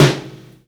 snare 5.wav